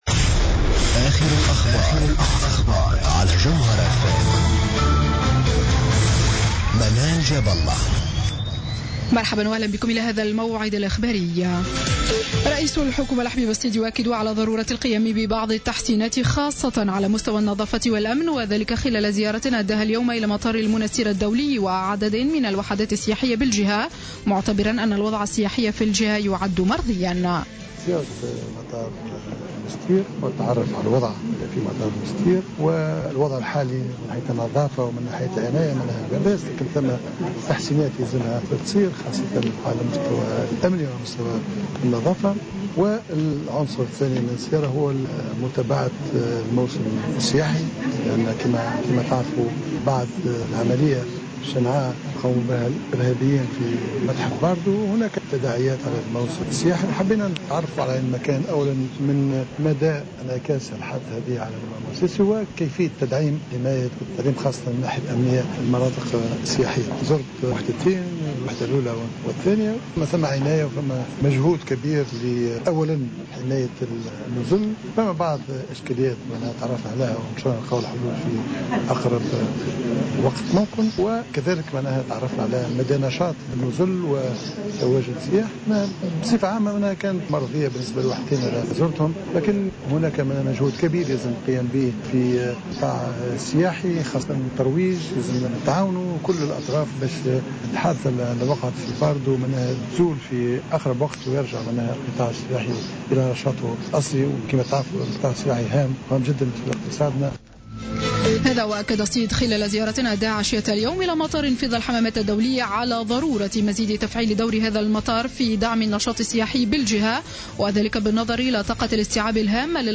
نشرة الأخبار السابعة مساء ليوم الاثنين 6 أفريل 2015